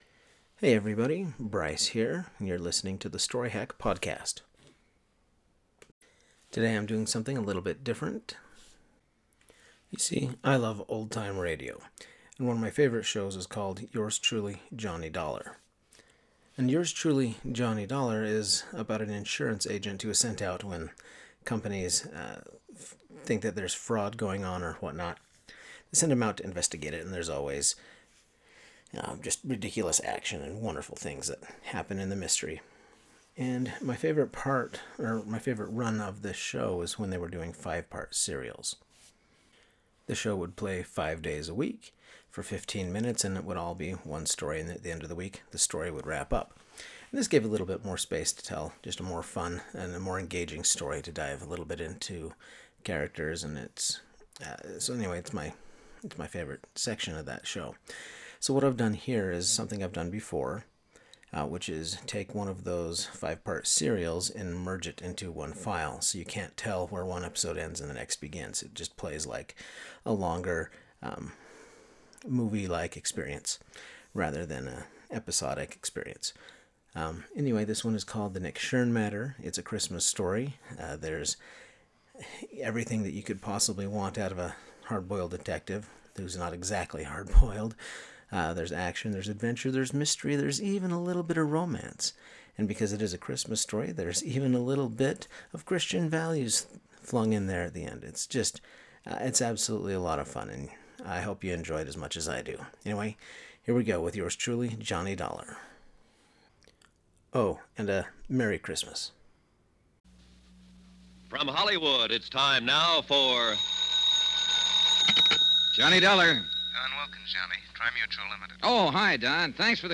Also, there are a lot of clean recordings of this show, so it’s easy for a non-super-fan to listen to.
A while back I decided to edit a couple of the serialized stories so that they would play as single seamless episodes.